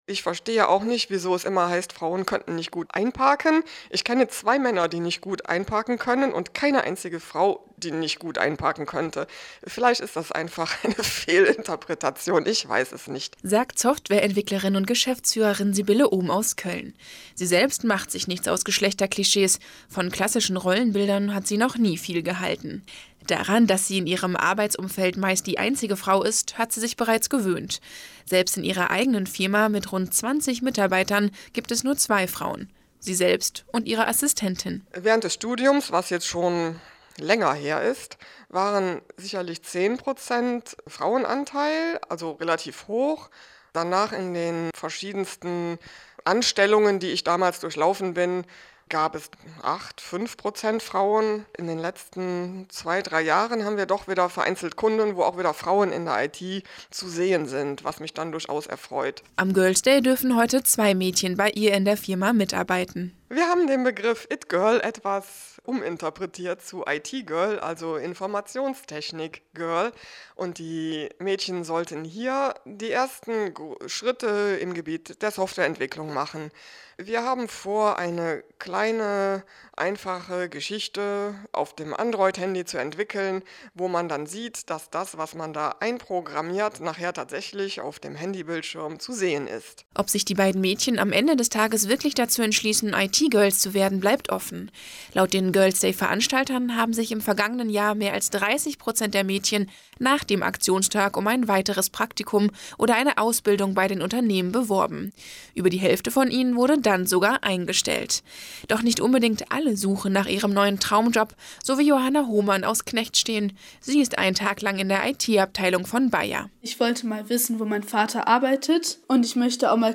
Girls´ Day 2018 - das Radio-Interview - anderScore
Das erste Mal dabei und schon im Radio!